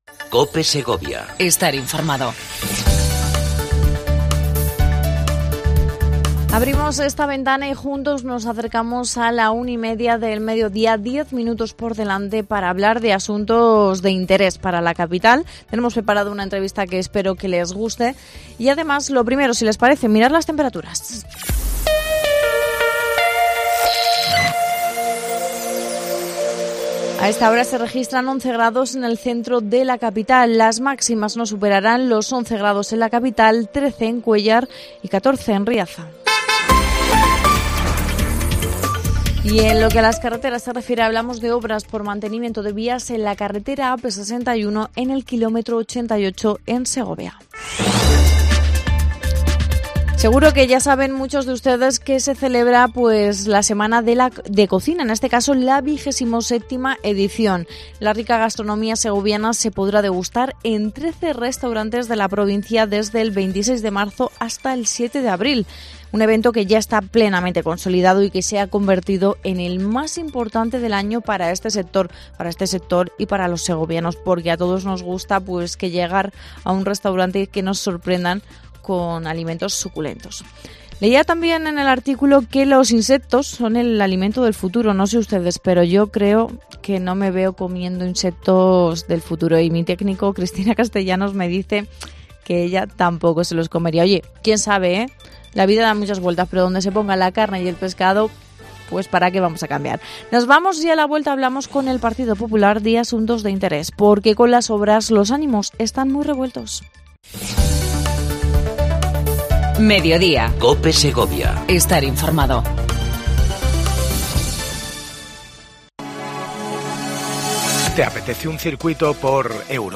AUDIO: Entrevista a Azucena Suárez, Viceportavoz del PP en el Ayuntamiento de Segovia.